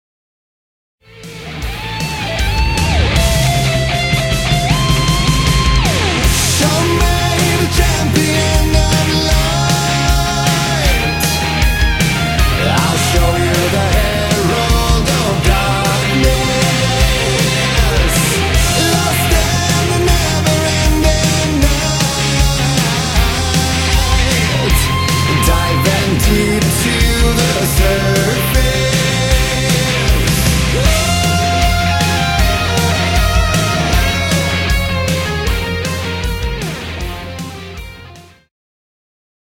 BPM156-156
Audio QualityPerfect (High Quality)
This is the chorus only version for practice!